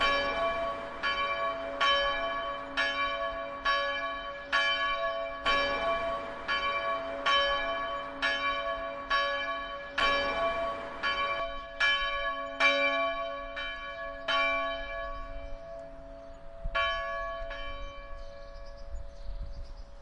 Churchbell 2
描述：在街道上的一个教堂。用Zoom H1 44 kHz 16位立体声WAV录制
Tag: 教堂 现场录制 城市